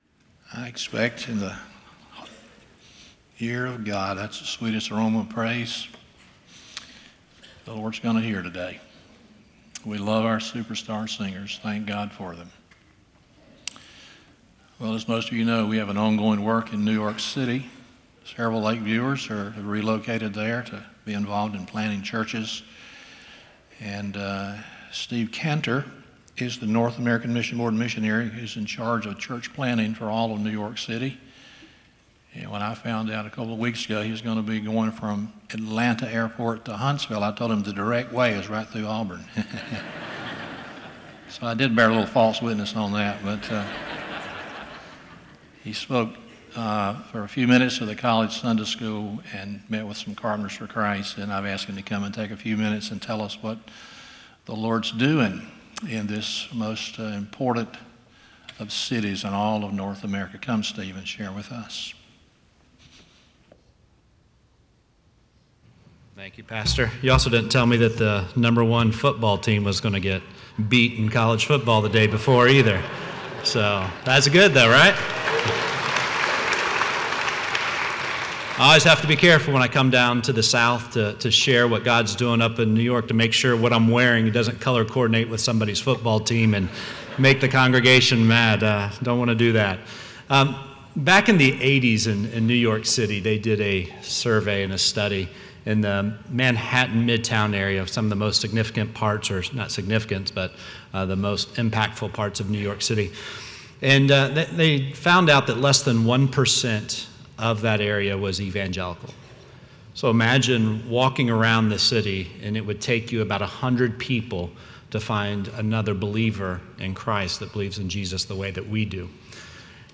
Hosea 4:6 Service Type: Sunday Morning 1